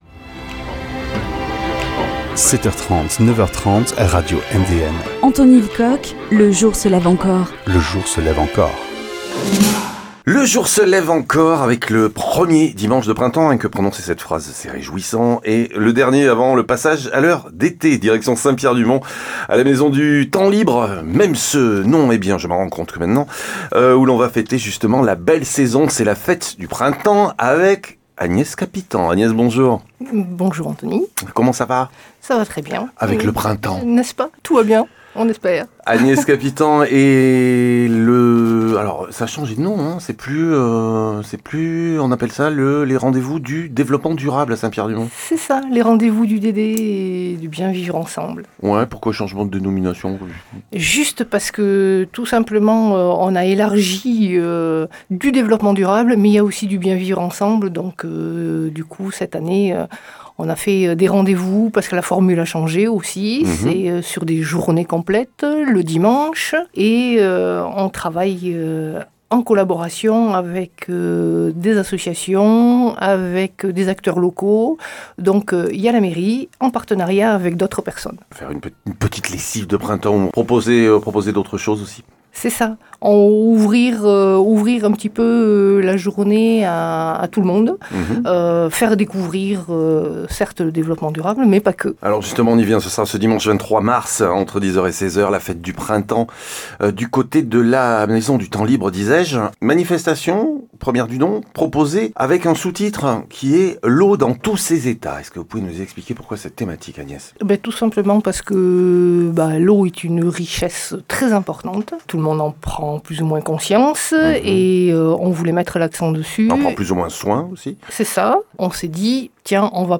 Entretiens au féminin